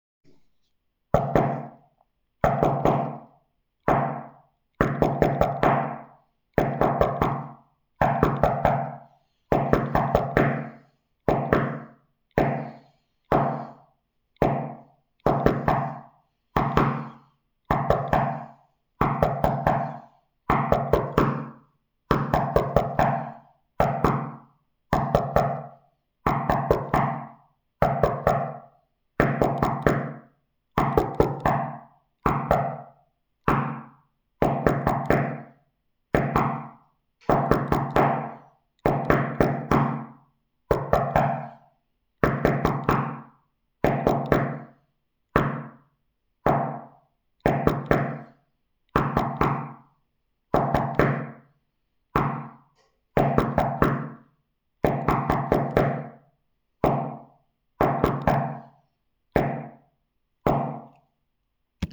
die opname is geen morsecode want morsecode bestaat uit lange en korte biepjes/signalen (dit's en dah's). Deze opname bevat 1 t/m 5 'klopjes' per keer dus je zoekt iets 'soortgelijks' (*kuch*).